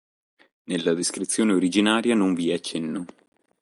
Read more Pron Adv Noun Frequency A1 Hyphenated as vì Pronounced as (IPA) /ˈvi/ Etymology See voi.